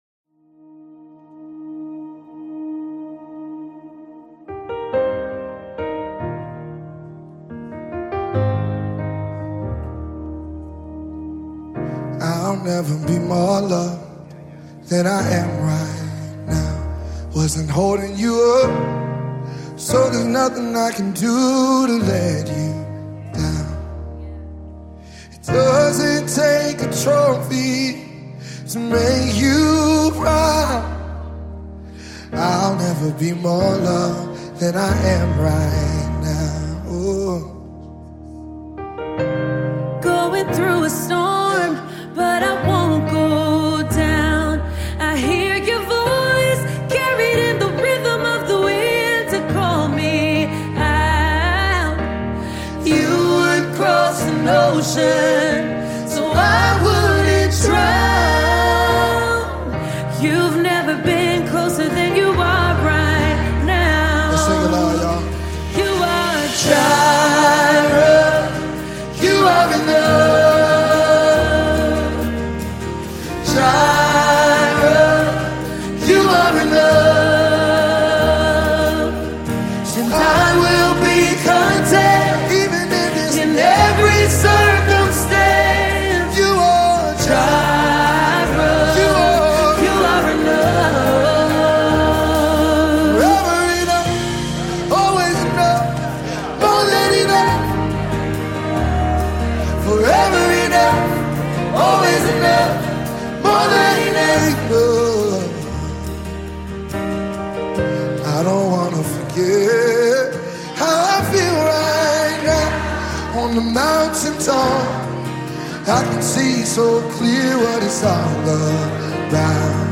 power-packed song
live visual